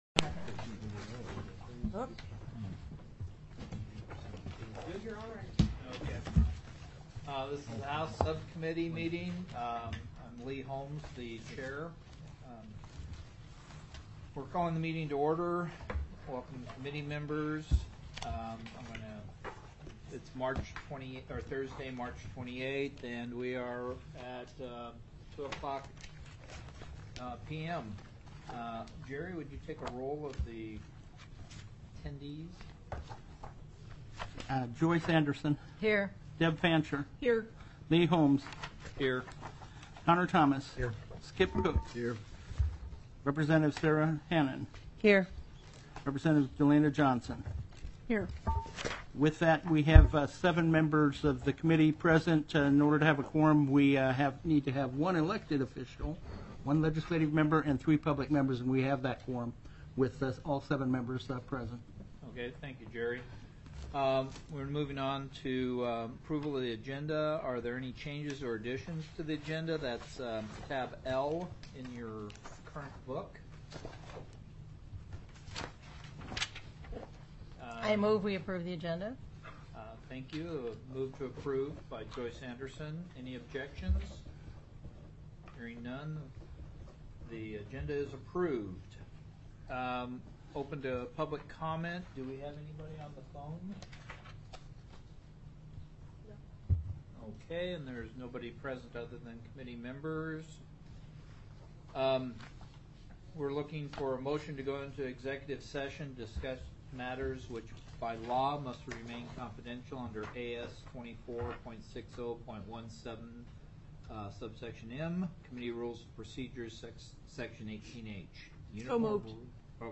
The audio recordings are captured by our records offices as the official record of the meeting and will have more accurate timestamps.
+ Public Comment TELECONFERENCED
House Subcommittee Meeting